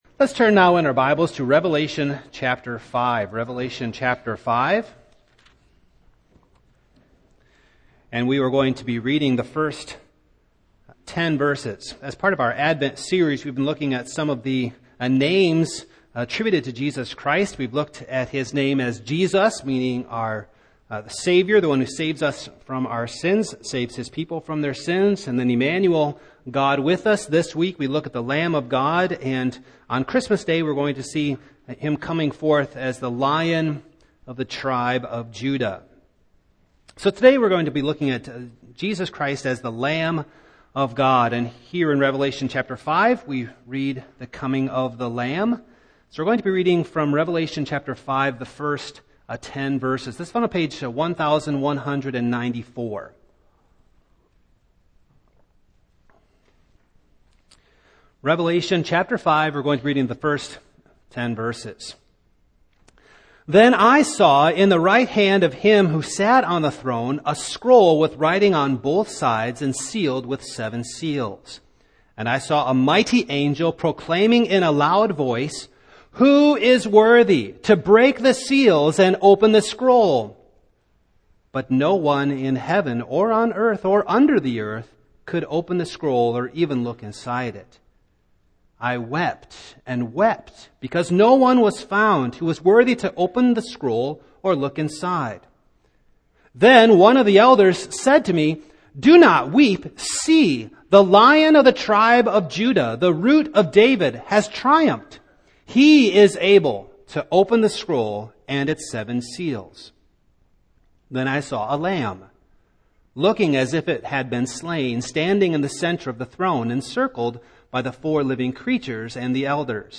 Single Sermons
Service Type: Morning